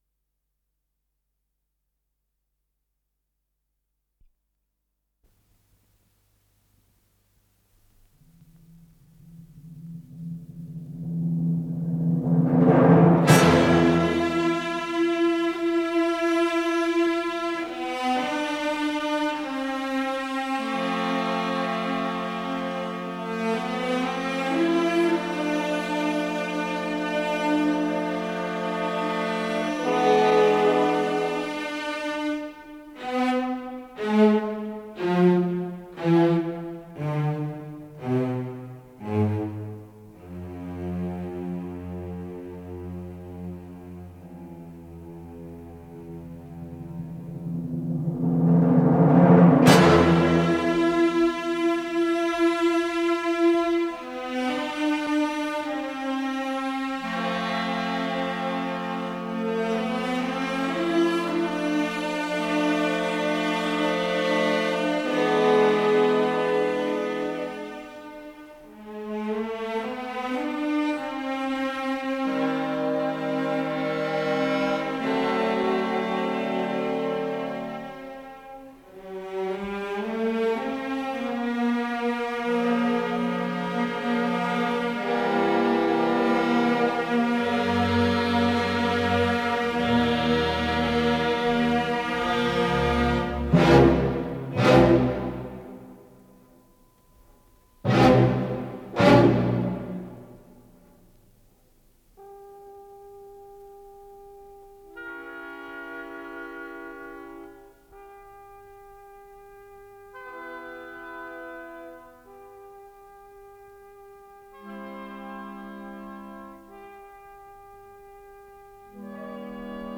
сопрано
бас